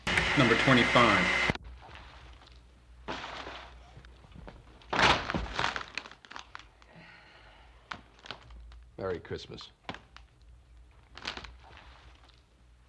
描述：树枝噼啪作响，轻微吱吱作响，叶子嘶嘶声，男声叹息，说着“圣诞快乐”。 这些是20世纪30年代和20世纪30年代原始硝酸盐光学好莱坞声音效果的高质量副本。 40年代，在20世纪70年代早期转移到全轨磁带。我已将它们数字化以便保存，但它们尚未恢复并且有一些噪音。